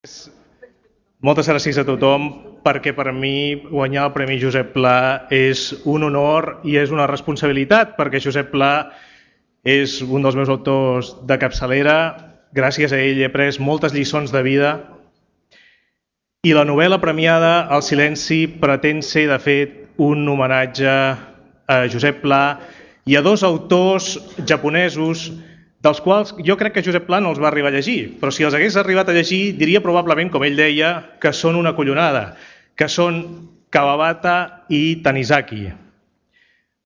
Al sopar literari hi van assistir el president de la Generalitat, José Montilla, el conseller de Cultura, Joan Manuel Tresserras, i el ministre de Cultura espanyol, César Antonio Molina.